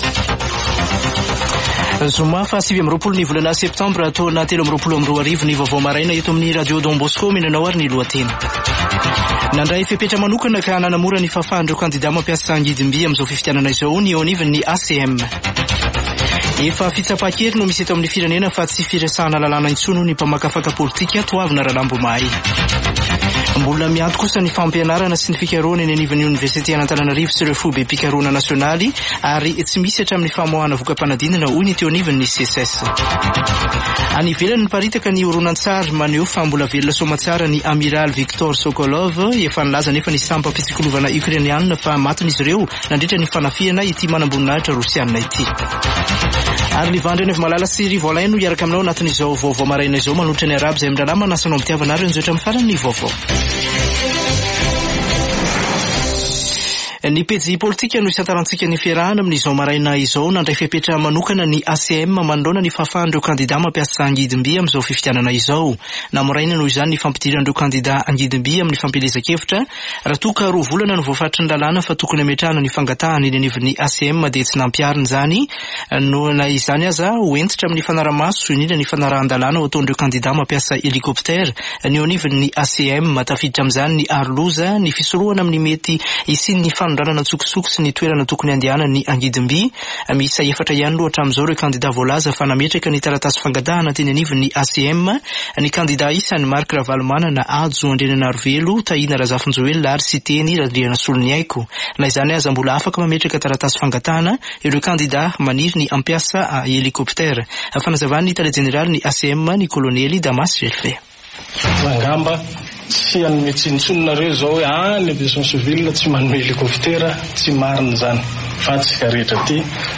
[Vaovao maraina] Zoma 29 septambra 2023